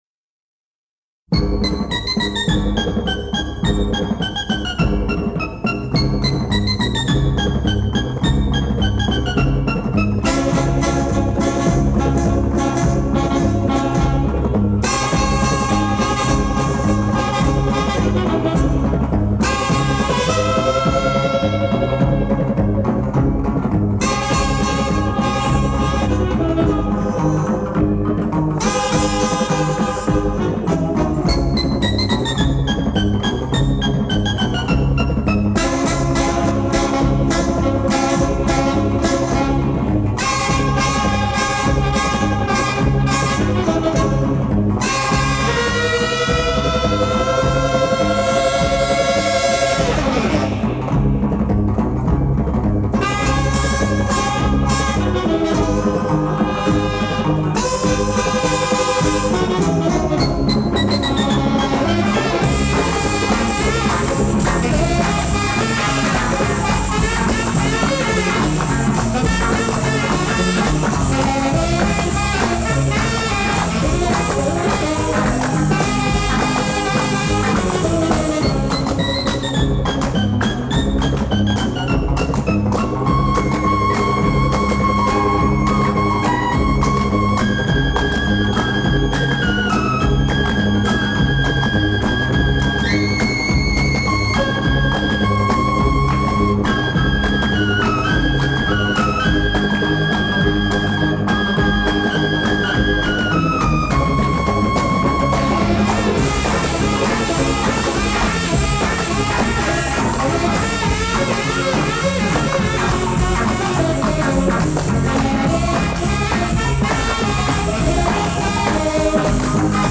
Обожаю surf-music.